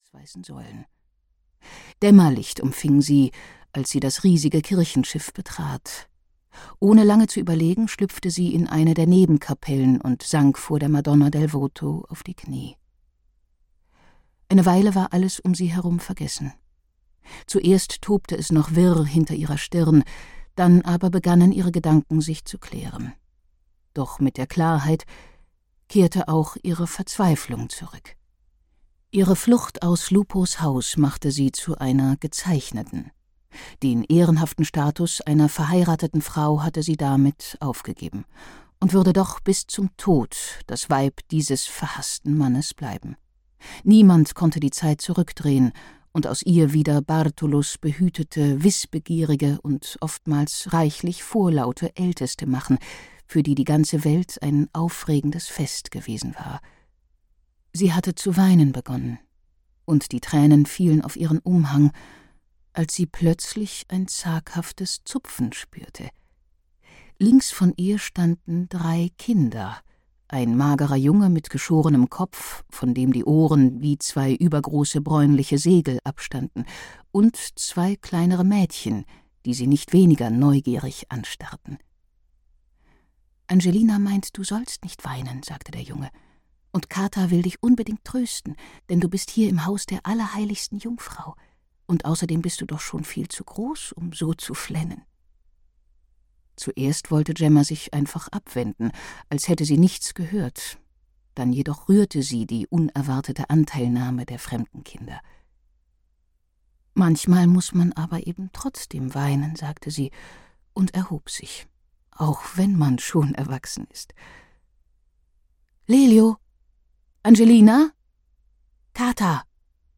Die Sünderin von Siena - Brigitte Riebe - Hörbuch